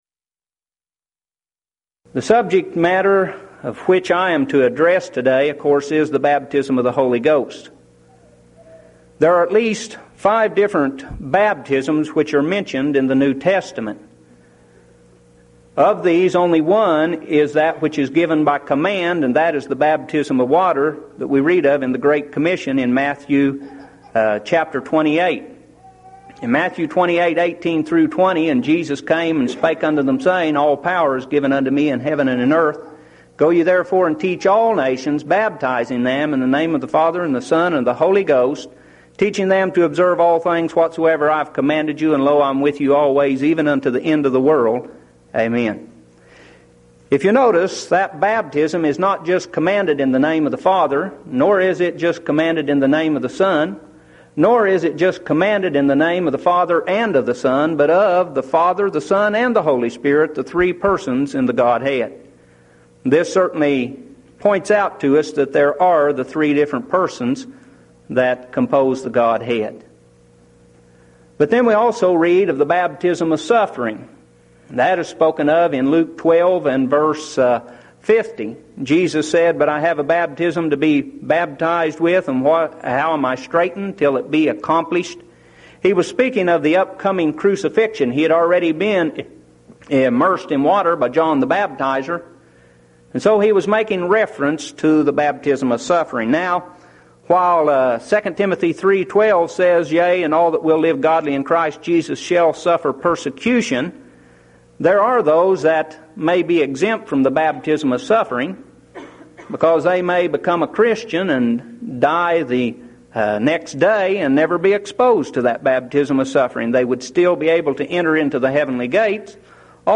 Event: 1997 Mid-West Lectures